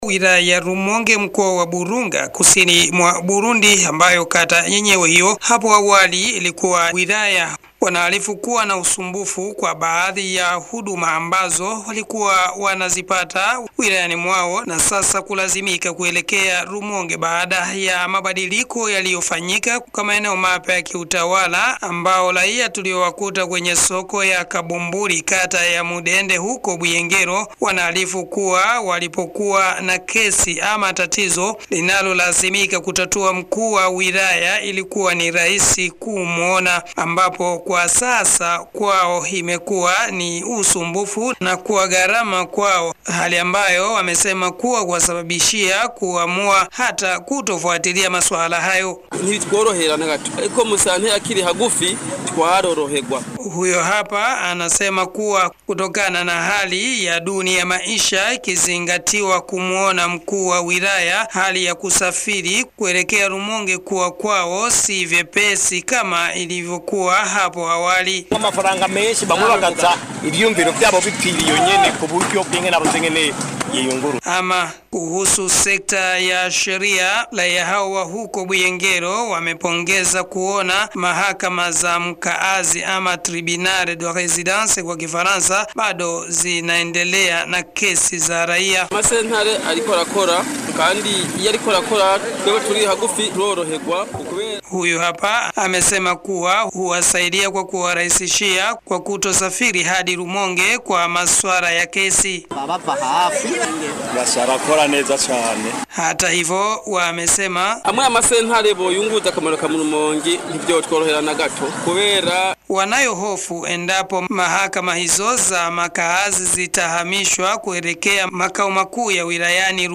Taarifa ya habari ya muungano wa redio ya tarehe 25 Septemba 2025